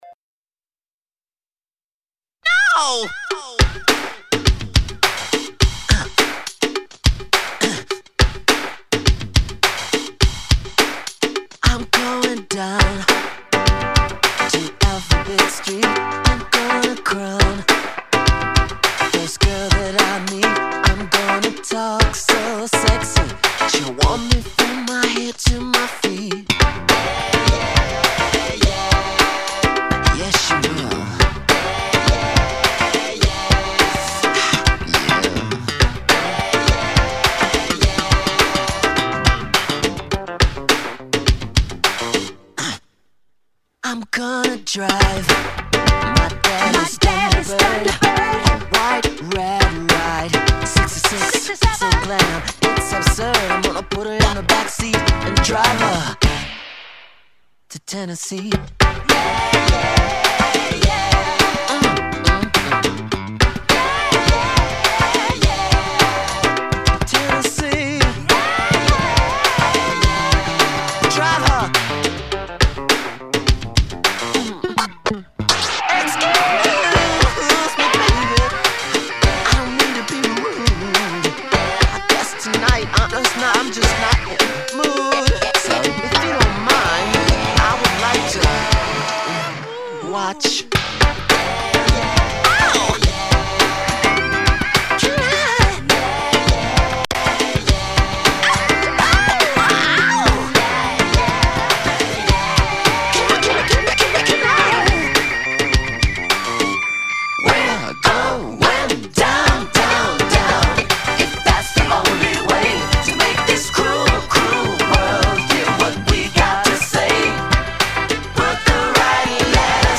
Il sabato del villaggio... una trasmissione totalmente improvvisata ed emozionale. Musica a 360°, viva, legata e slegata dagli accadimenti.